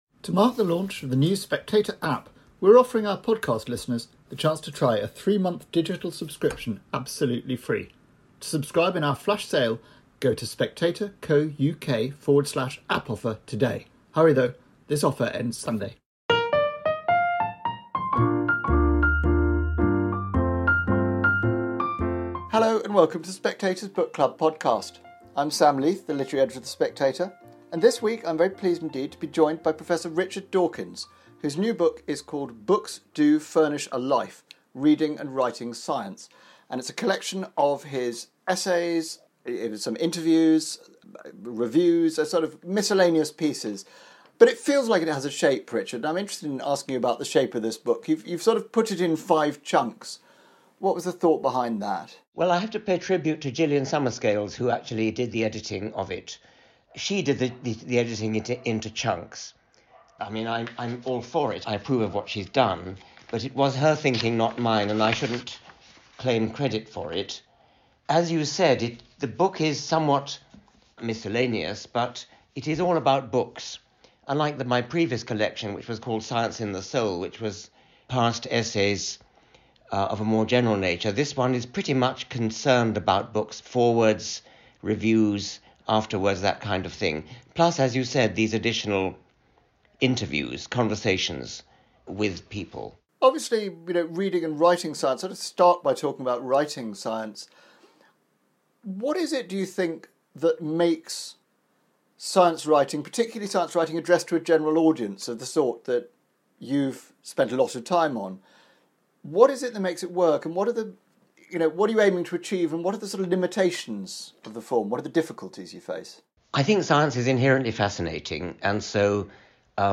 News Commentary, News, Daily News, Society & Culture